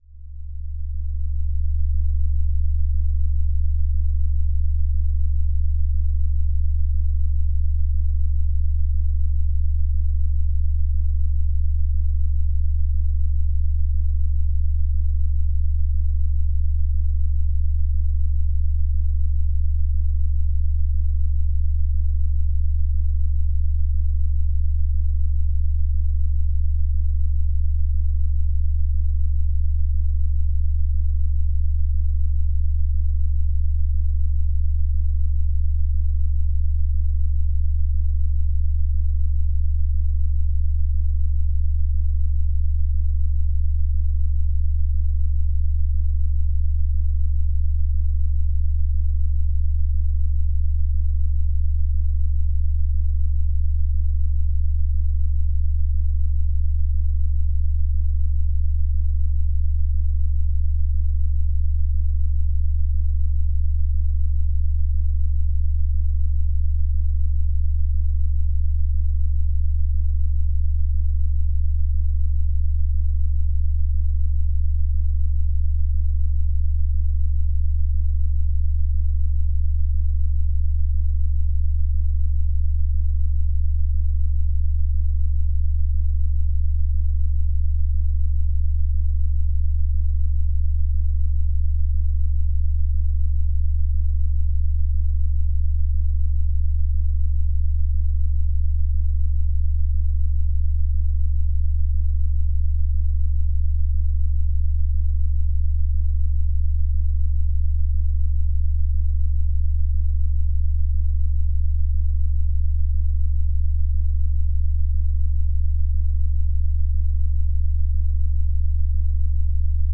CHANTS VIBRATOIRES